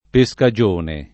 pescagione [ pe S ka J1 ne ] s. f.